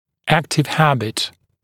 [‘æktɪv ‘hæbɪt][‘эктив ‘хэбит]активная привычка, действующая привычка